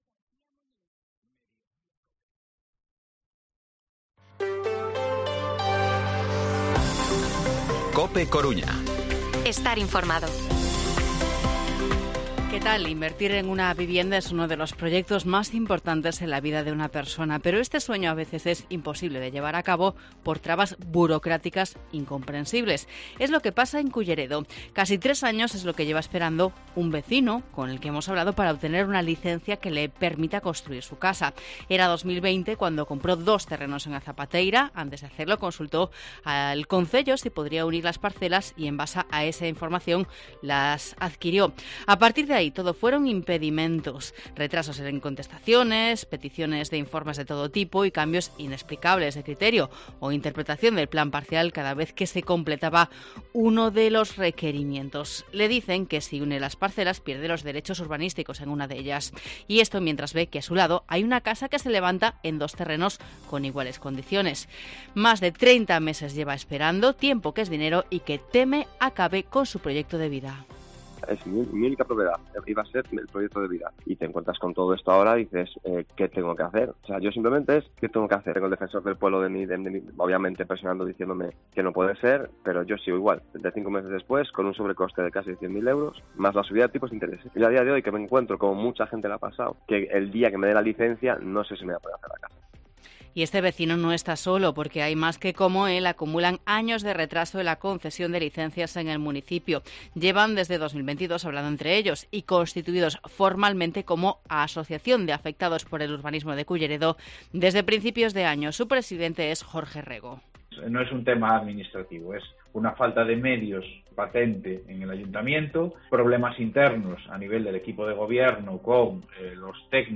Informativo Mediodía COPE Coruña jueves, 25 de mayo de 2023 14:20-14:30